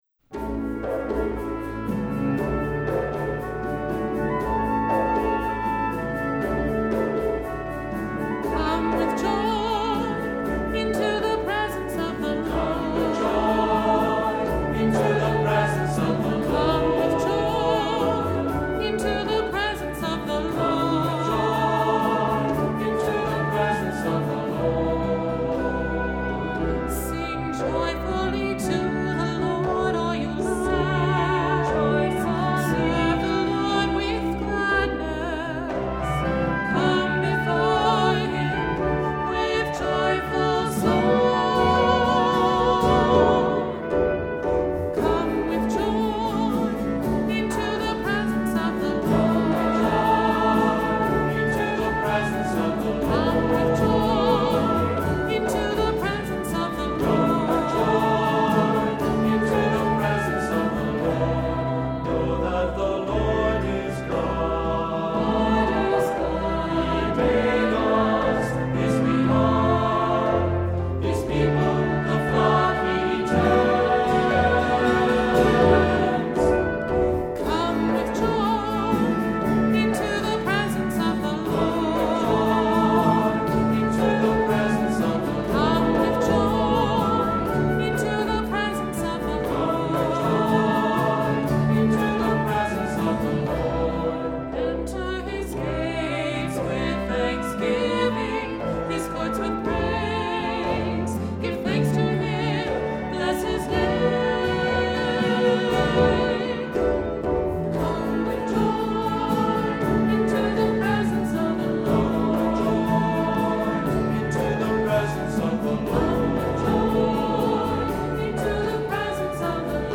Accompaniment:      Keyboard
Music Category:      Christian
Flute, violin and cello parts are optional.